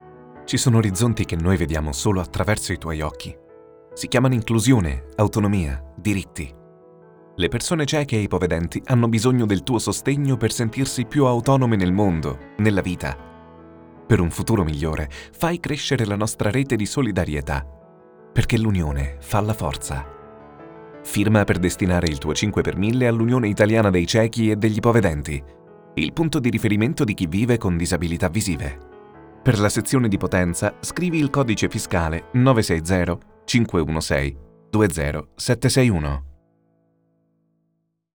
Spot-Radio-85-Potenza.wav